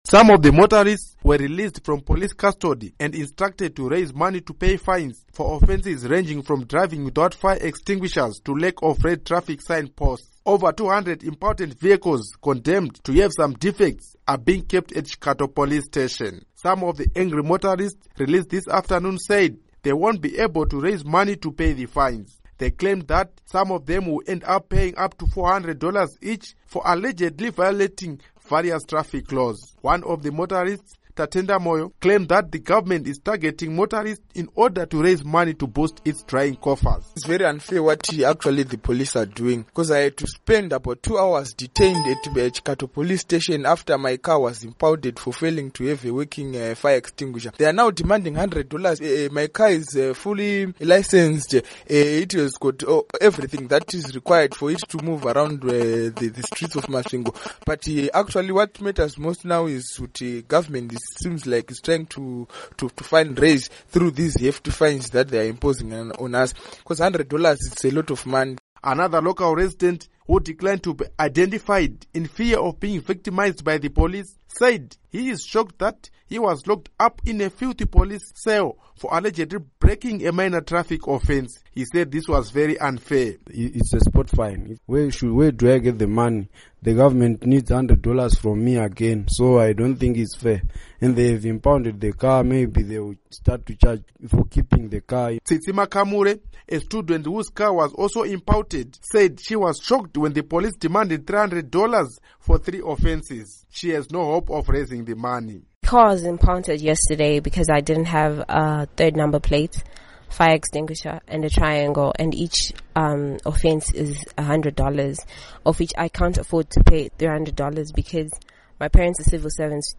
Report on Arrest of Motorists